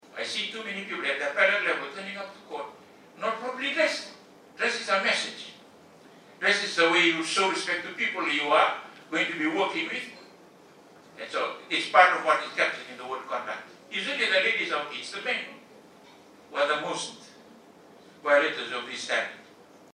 During his presentation at the ODPP Annual Conference, Mataitoga underscores the significance of adhering to the principles of right conduct and ethical behaviour.
Justice of Appeal Isikeli Mataitoga.